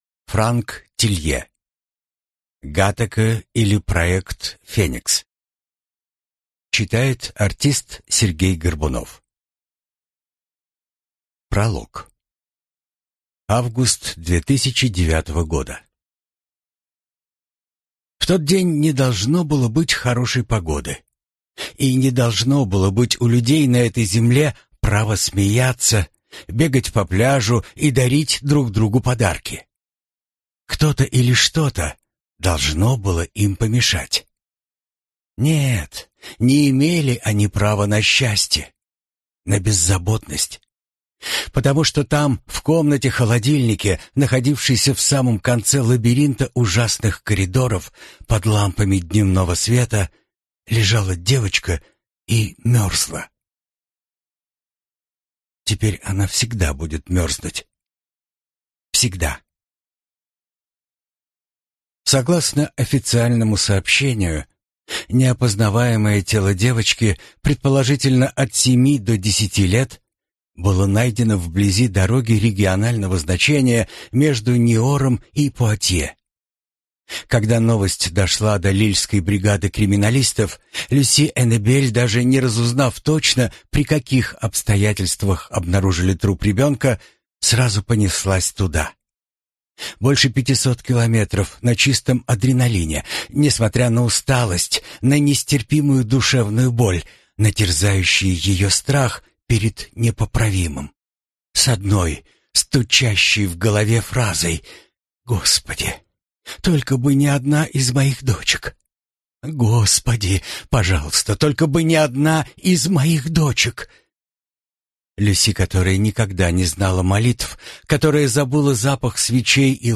Аудиокнига Gataca, или Проект «Феникс» | Библиотека аудиокниг